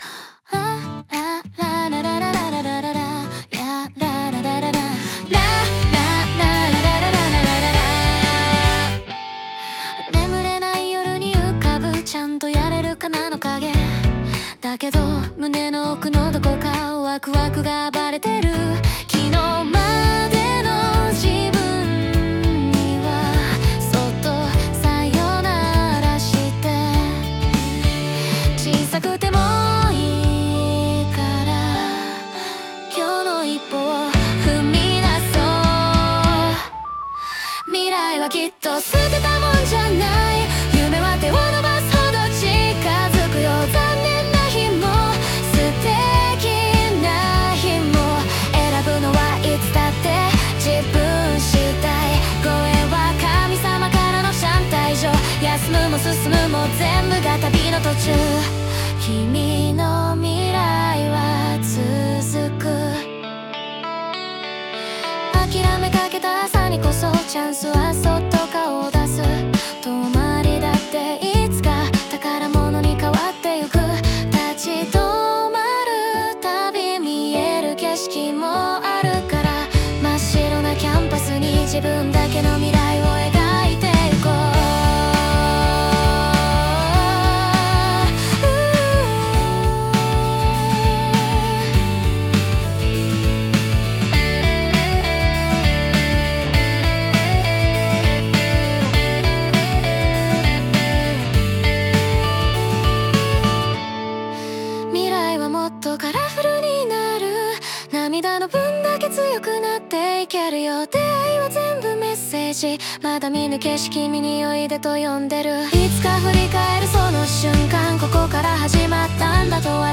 最近、元気のない若い人向けに自分で書いた歌詞を並べ替え、AIに曲として仕上げてもらったところ、まるで本物の歌手が歌っているようなクオリティで返ってきて驚愕してしまいました。ブレス音（息づかい）までリアルで、初めて聴いたときは思わず鳥肌が立ちました。